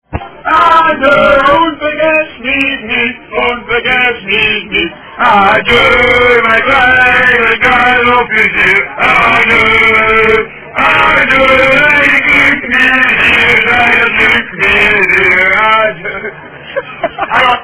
Marktpubliek vermaakt door Kandidaten
Duitsche liederen (00:17 18KB MP3), gemixt met onvervalschte Ius-liederen.
De Turkse voorbijgangers schenen het niet helemaal te begrijpen, maar de ouderen van dage gingen ‘way back’ bij sommige ‘golden oldies’ die de Kandidaten ten gehore brachten en brabbelden vrolijk mee.
De prachtige vocals van Kandidaten spreken jong tot oud aan.